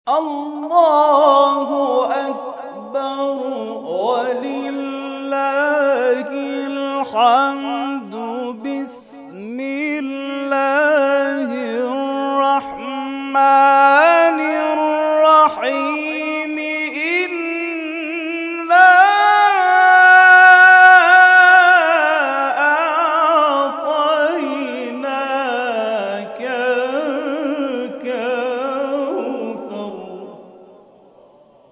در ادامه قطعات تلاوت این کرسی ها ارائه می‌شود.